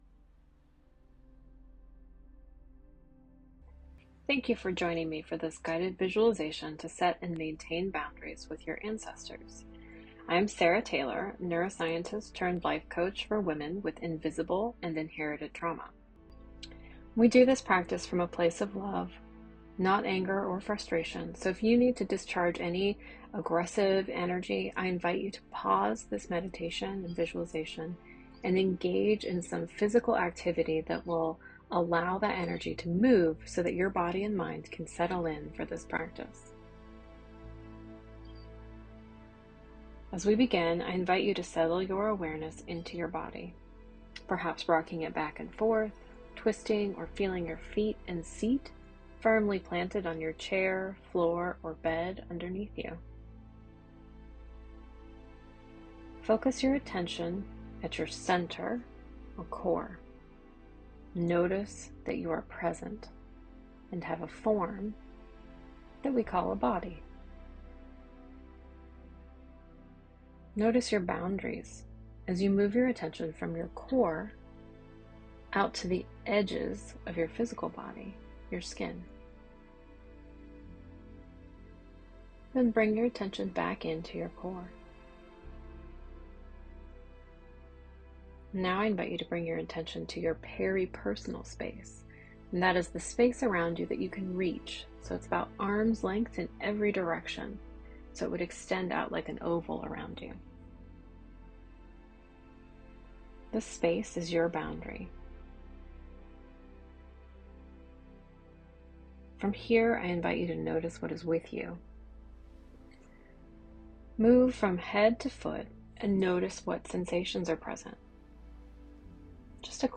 This guided meditation will help you claim your space and create compassionate boundaries with your burdened ancestors .